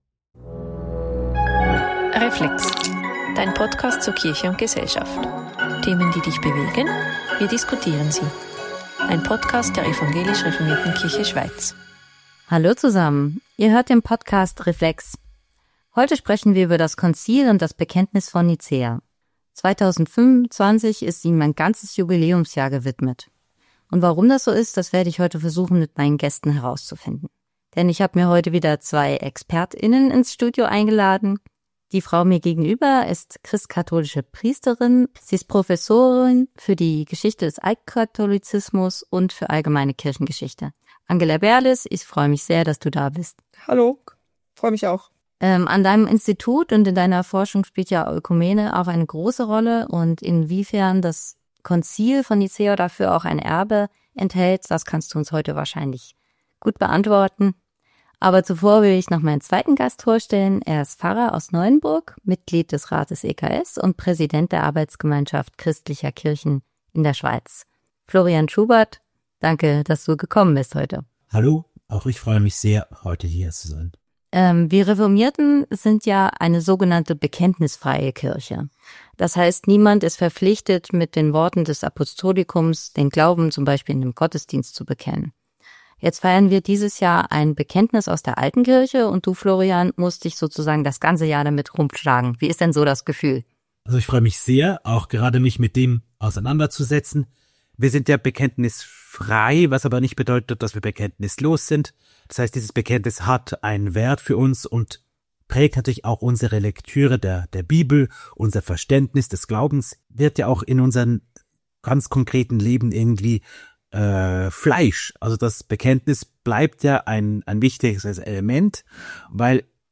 diskutiert